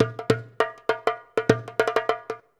100DJEMB30.wav